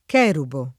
k$rubo] (con pn., per es., piana in Dante, ora piana ora sdrucciola nel D’Annunzio); nel Pascoli anche cherub [ker2b]